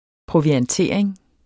Udtale [ pʁovianˈteɐ̯ˀeŋ ]